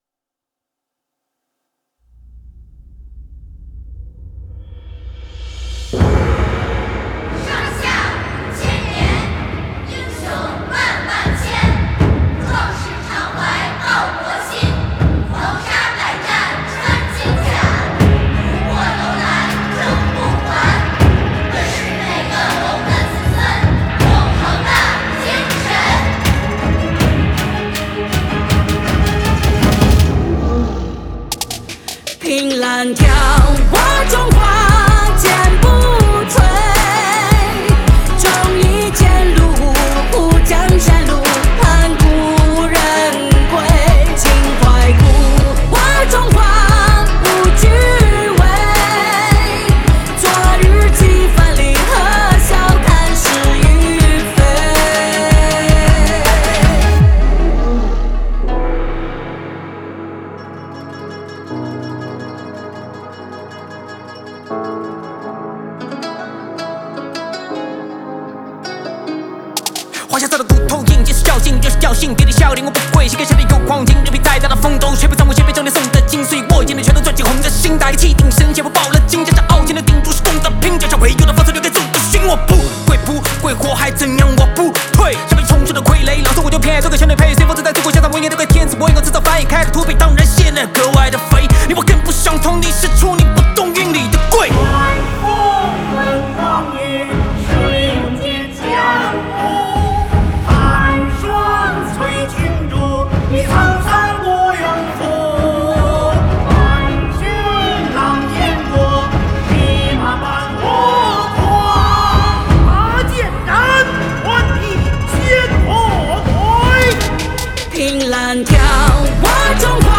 Ps：在线试听为压缩音质节选，体验无损音质请下载完整版
戏曲演唱
童声合唱